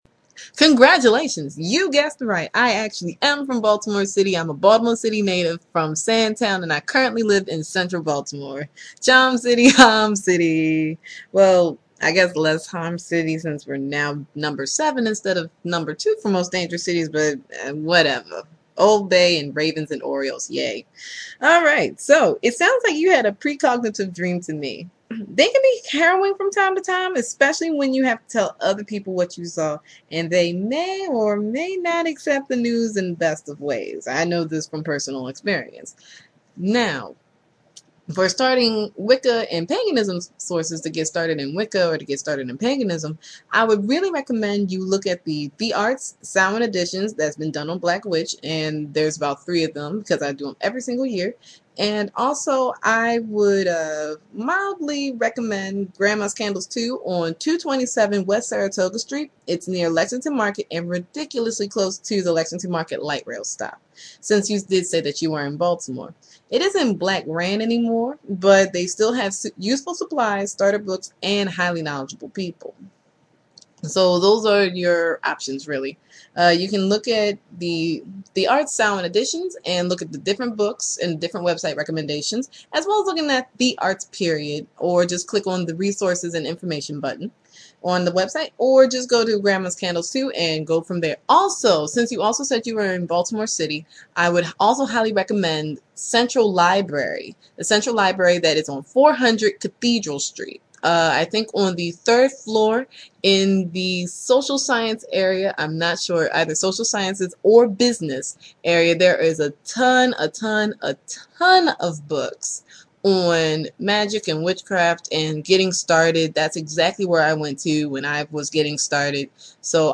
Good Gods, though! I sound seriously Southern!